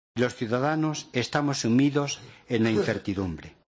En la Asamblea Plenaria de la Conferencia Episcopal Española.